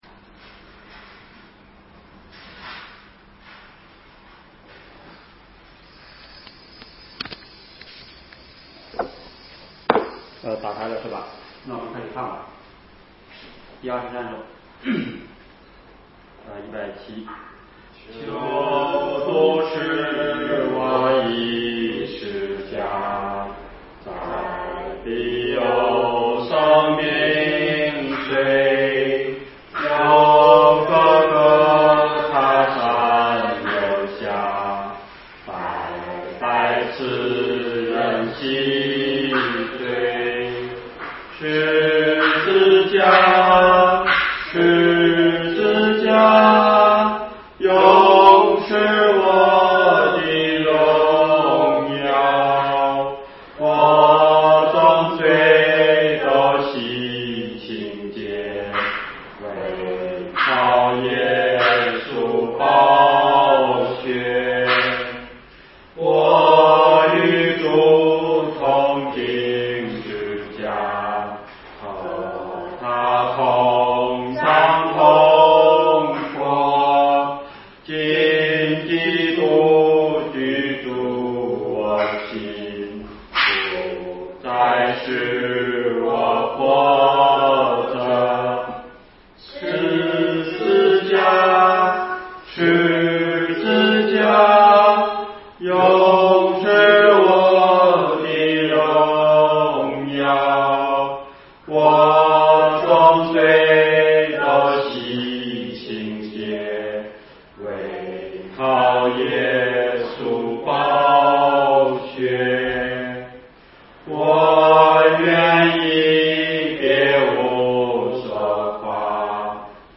创世记第23讲 2020年4月28日 下午3:34 作者：admin 分类： 创世记小组圣经讲道 阅读(3.46K